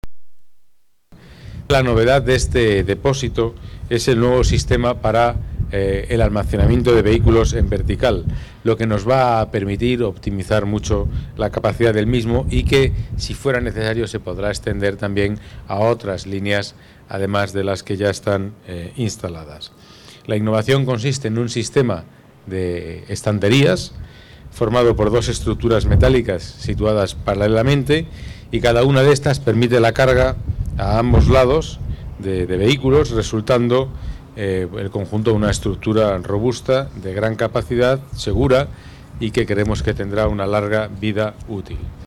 Nueva ventana:Declaraciones del concejal de Seguridad, Pedro Calvo: Vehículos Intervenciones Policía Municipal